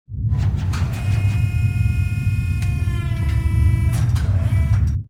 Repair3.wav